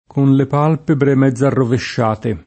mezzo [m$zzo] agg. («metà; medio») — elis.: mezz’agosto, mezz’ala, mezz’ombra, mezz’ora (tutte locuz. scritte anche unite); mezz’estate; mezz’annata; mezz’e mezzo; a mezz’aria (meno com. a mezzaria), a mezz’asta; di mezz’età — altri casi d’elis. nell’uso fam. o lett.: una mezz’oncia di fiele [una mHzz 1n©a di fL$le] (Redi); e con mezzo in funzione di avv.: a questo modo mi pare essere mezz’uccellato [a kkU%Sto m0do mi p#re $SSere m$zz u©©ell#to] (Gelli); con le palpebre mezz’arrovesciate [